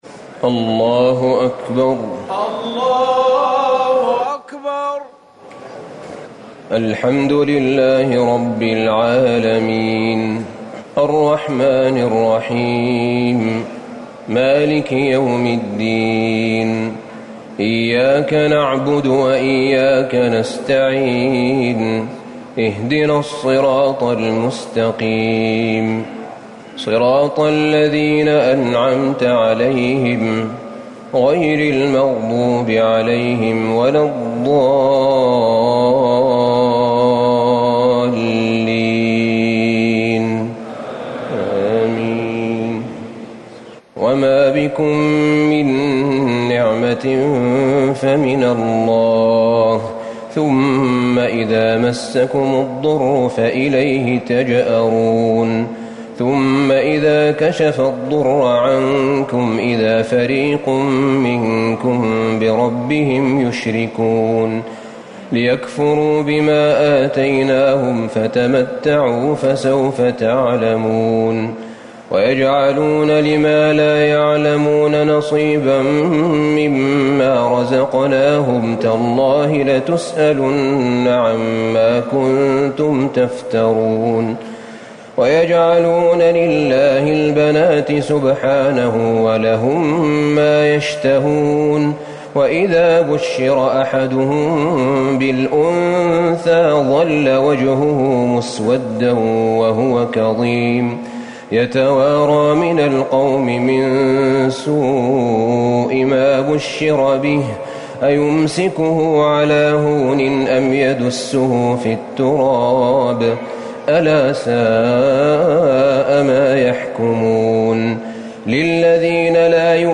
ليلة ١٣ رمضان ١٤٤٠ سورة النحل ٥٣-١٢٨ > تراويح الحرم النبوي عام 1440 🕌 > التراويح - تلاوات الحرمين